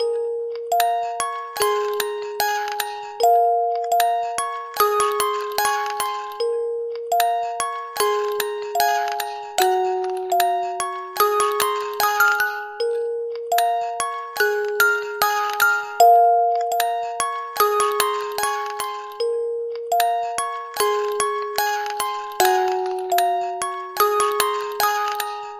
标签： 75 bpm Hip Hop Loops Bells Loops 4.31 MB wav Key : C
声道立体声